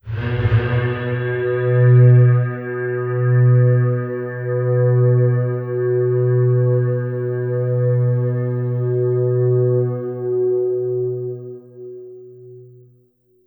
bellpad.100bpm.wav